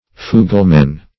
Fuglemen - definition of Fuglemen - synonyms, pronunciation, spelling from Free Dictionary
Fugleman \Fu"gle*man\, n.; pl. Fuglemen.